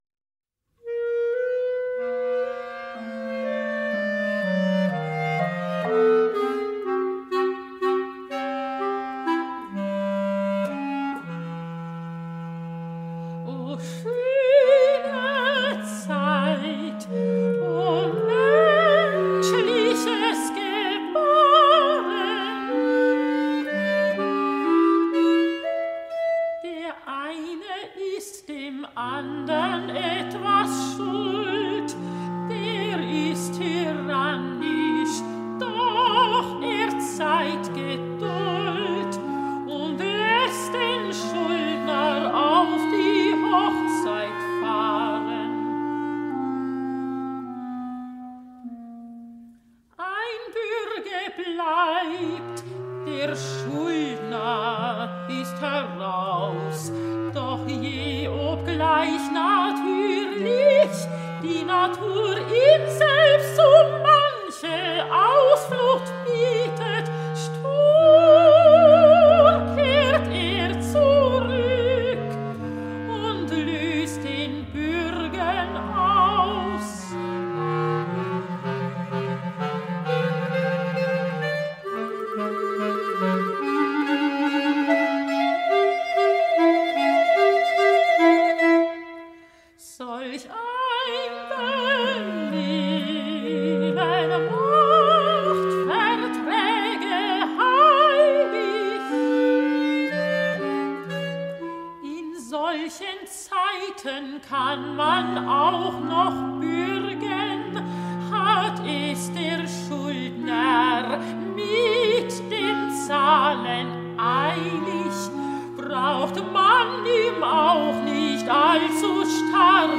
Ruhig, gehende
(arr. for piano and voice) (recording link)